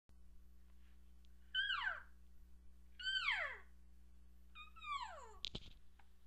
Calfmew
Calf Elk Sounds Calf Mew The Elk calves make some of the same sounds as the cows. The difference is the higher pitch and shorter duration than the cow sounds.